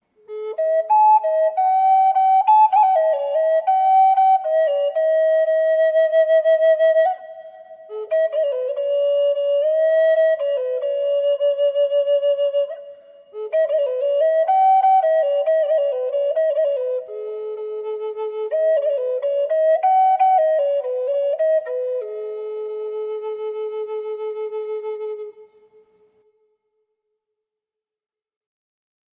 key of A & A#